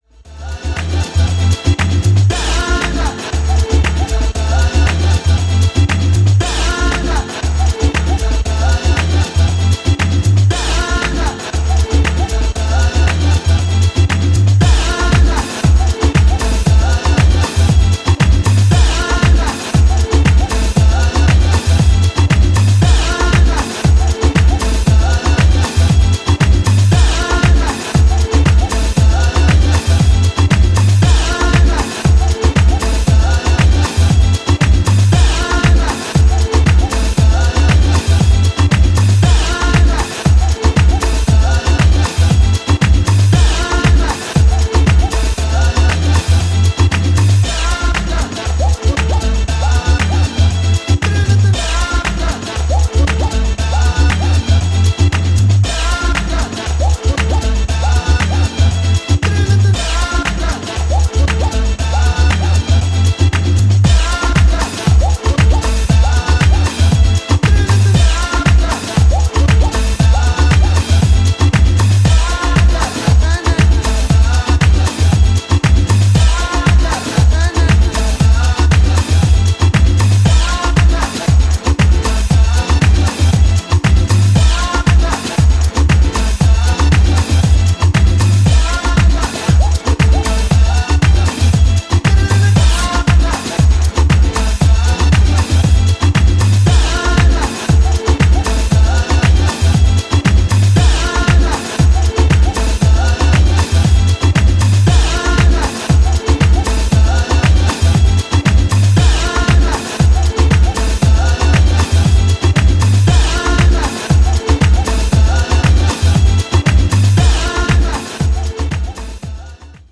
ジャンル(スタイル) DEEP HOUSE / HOUSE / DETROIT